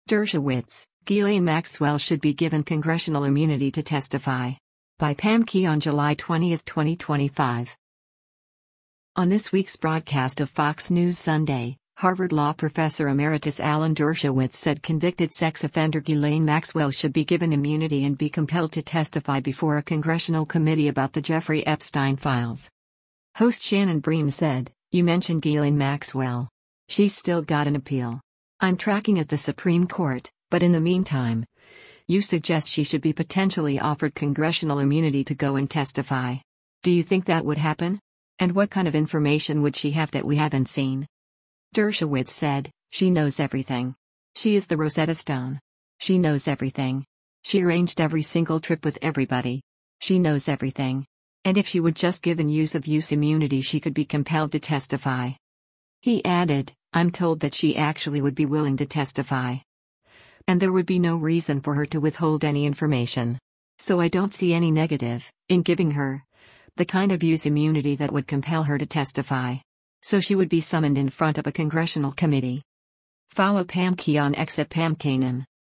On this week’s broadcast of “Fox News Sunday,” Harvard law professor emeritus Alan Dershowitz said convicted sex offender Ghislaine Maxwell should be given immunity and be compelled to testify before a congressional committee about the Jeffrey Epstein files.